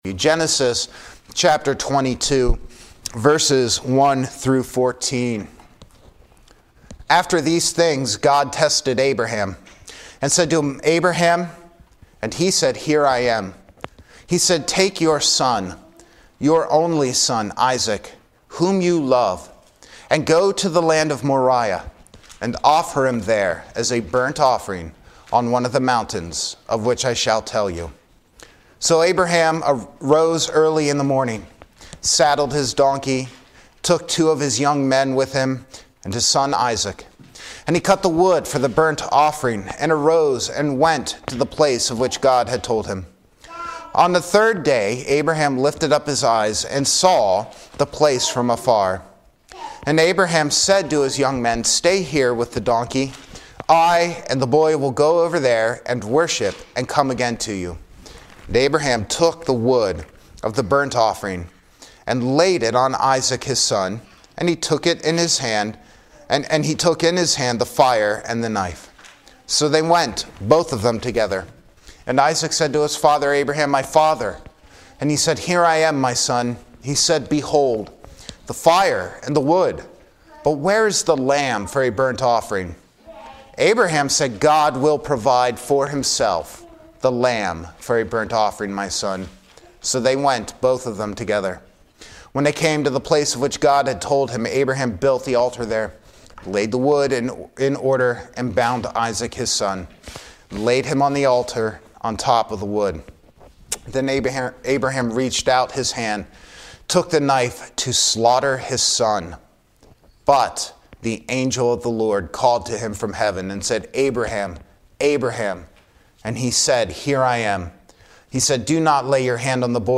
Good Friday Service 2025 (Genesis 22:1-14)